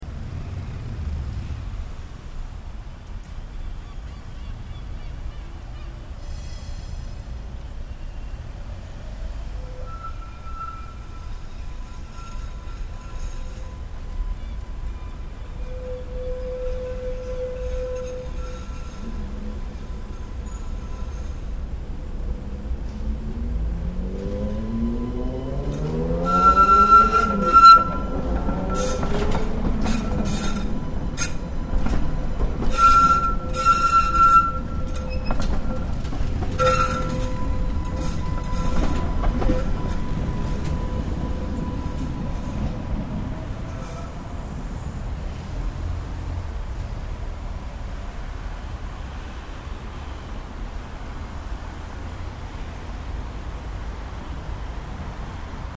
Tram in Riga, Latvia